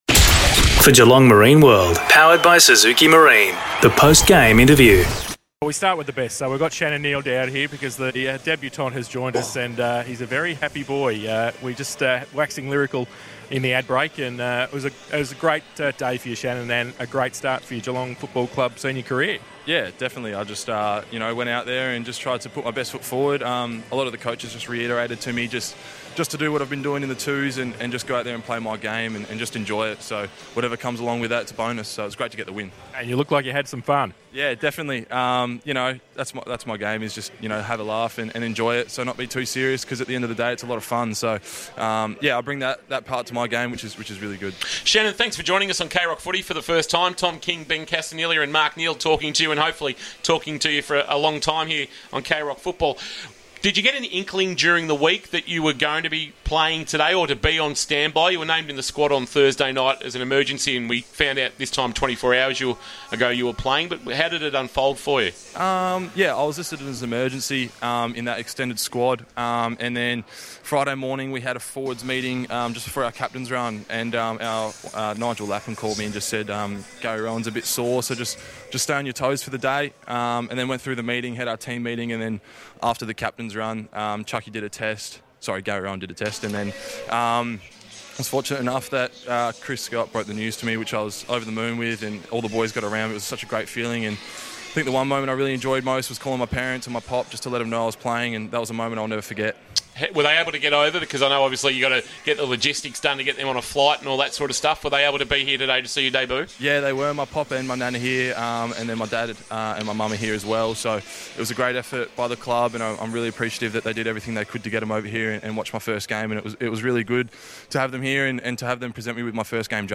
2022 - AFL ROUND 11 - GEELONG vs. ADELAIDE: Post-match Interview